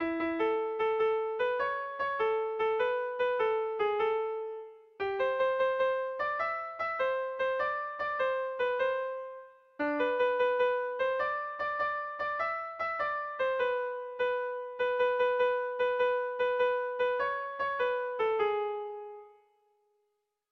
Air de bertsos - Voir fiche   Pour savoir plus sur cette section
Sentimenduzkoa
Zortziko ertaina (hg) / Lau puntuko ertaina (ip)
ABDE